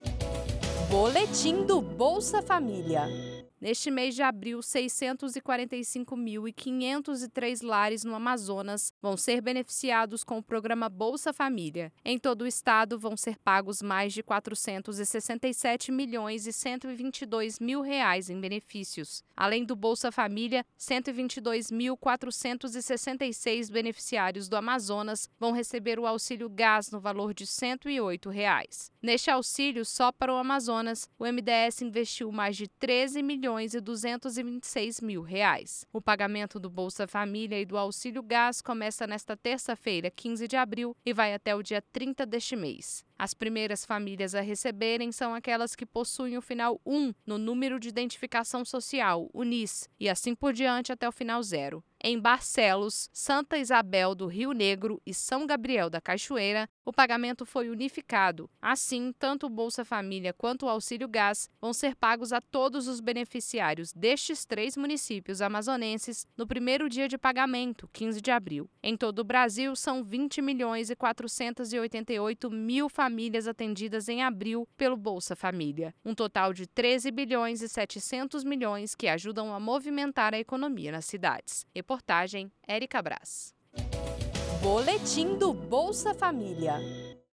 Boletim sobre o calendário de pagamento do Bolsa Família e do Auxílio Gás em abril de 2025.